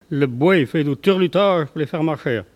Il chante pour faire avancer les boeufs
traction bovine